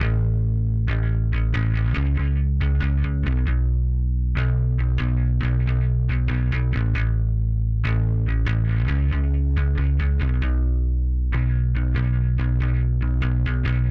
Horizon_29_138bpm_Emin.wav